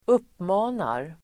Uttal: [²'up:ma:nar]